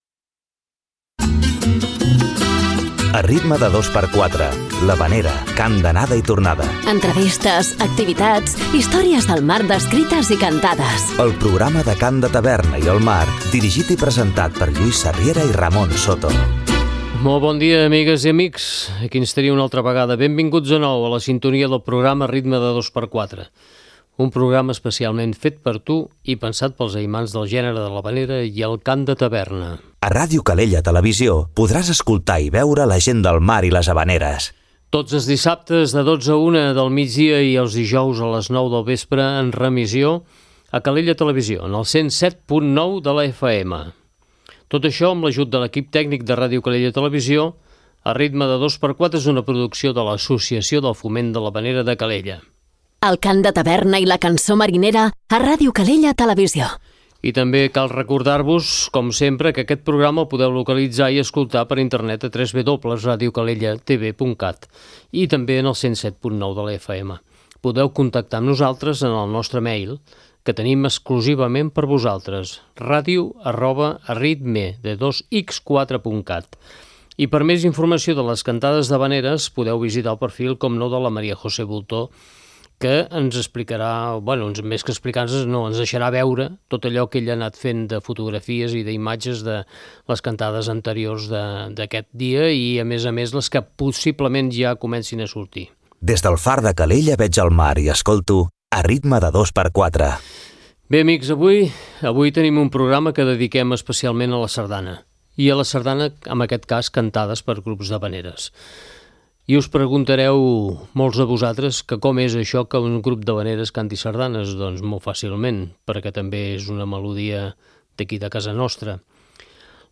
Aquesta setmana dediquem el programa a les sardanes cantades pels grups d'havaneres.